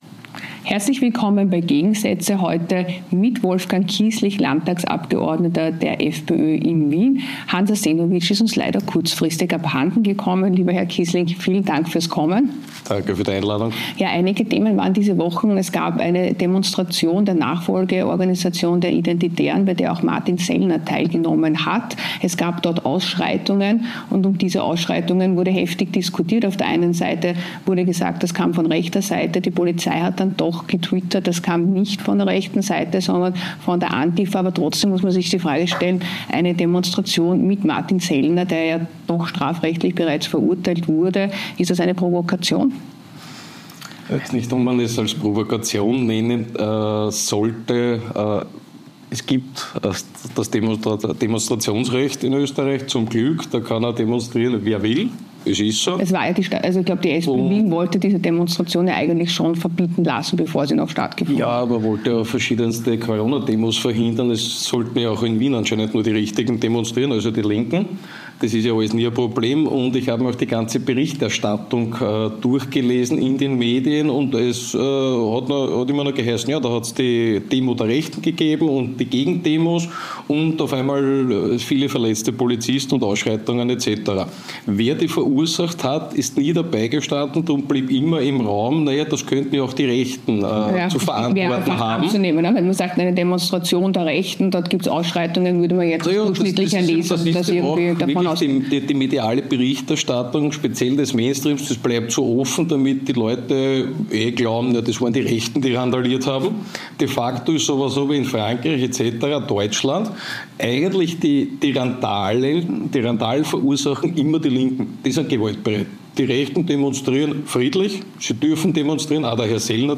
Beschreibung vor 1 Jahr In dieser Folge von "Gegensätze" begrüßt eXXpress TV den Landtagsabgeordneten Wolfgang Kießlich (FPÖ) zu einer kontroversen Diskussion über aktuelle politische Ereignisse. Die Themen reichen von den jüngsten Demonstrationen der Identitären und der Rolle der Antifa bei den Ausschreitungen bis hin zu der medialen Berichterstattung und den Konsequenzen für die politische Landschaft in Österreich und Deutschland.